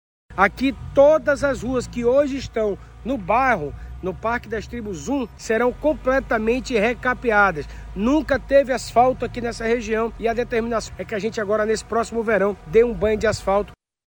Sonora-2-Renato-Junior-–-secretario-da-Seminf.mp3